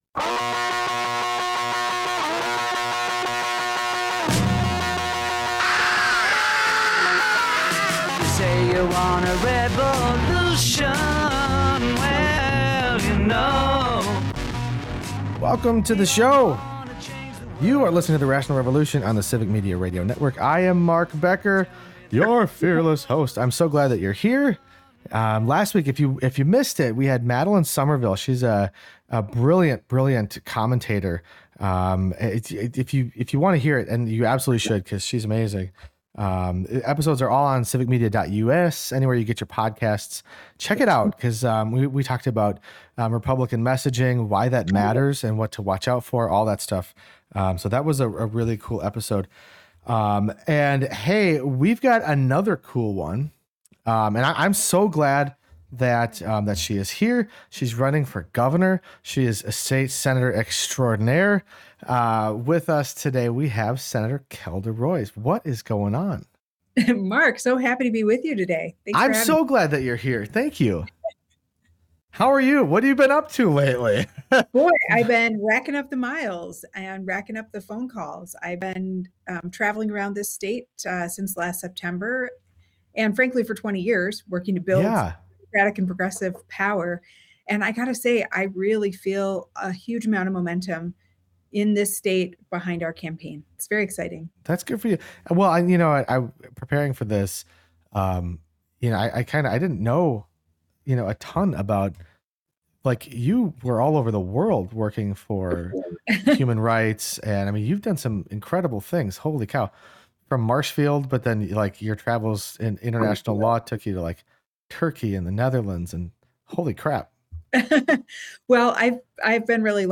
State Senator Kelda Roys joins the show to talk about her campaign for Governor. She talks about the responsibilities of the job, why experience matters, and how she will improve the lives of Wisconsinites if she's elected. They also get into a spirited discussion about the recent ICE activity and how it is unlawful, as well as how Wisconsin's backwards laws regarding women's health need to change.